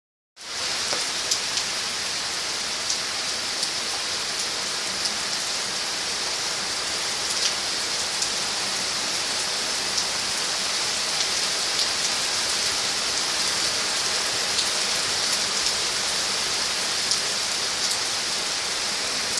大雨
描述：我的花园里有大雨。